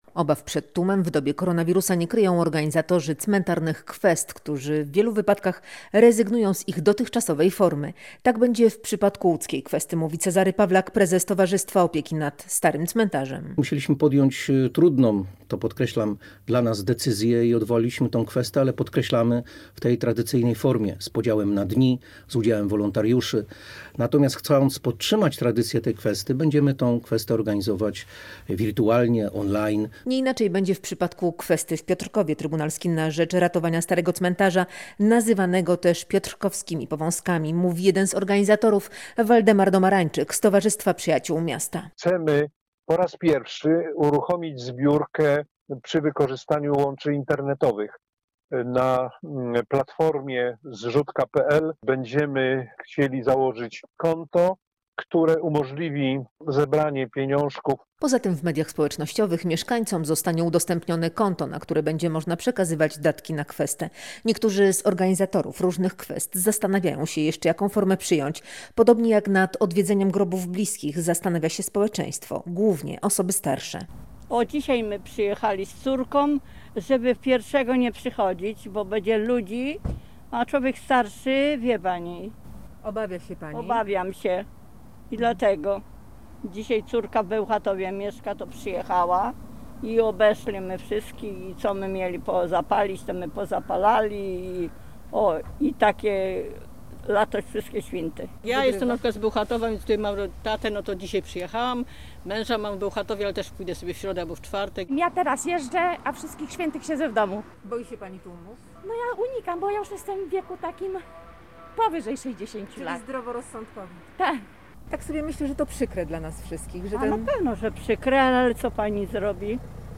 Posłuchaj relacji i dowiedz się więcej: Nazwa Plik Autor Internetowa kwesta zamiast tej tradycyjnej, przed bramami piotrkowskich cmentarzy audio (m4a) audio (oga) Tegoroczna kwesta, choć w tym roku w sieci, będzie 33. taką zbiórką.